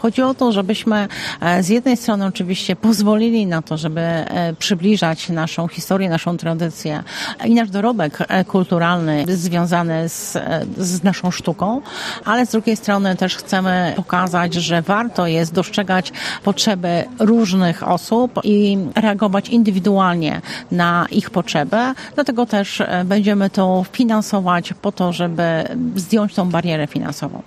Janina Ewa Orzełowska, członkini zarządu województwa mazowieckiego dodaje, że program pozwala korzystać z wartości kulturalnych: